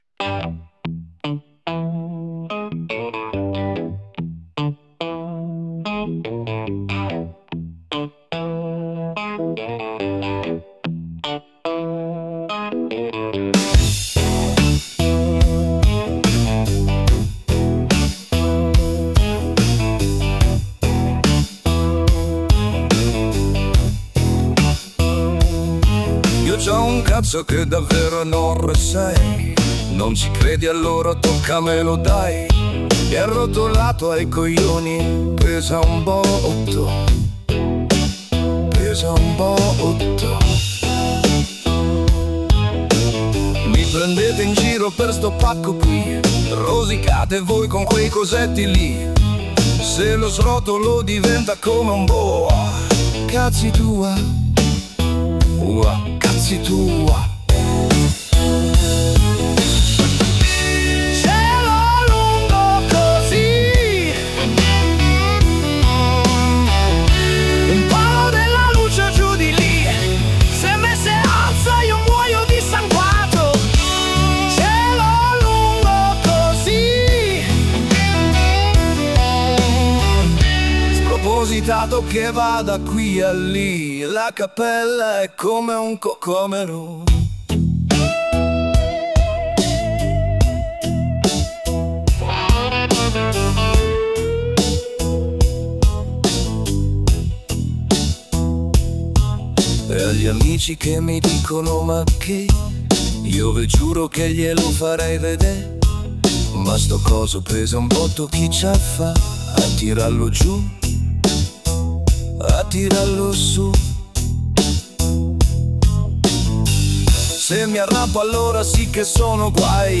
ho provato a far suonare l’inno di agorà a uno famoso per il suo talento con la chitarra, stando ben attento nel prompt a non nominare mai direttamente l’artista o qualche cosa che potesse ricondurre direttamente a lui, ma esclusivamente descrivendo a parole lo stile chirarristico (uso pesante di feedback, wha wha, bending esagerato ecc) e il tipo di canto da usare nel ritornello.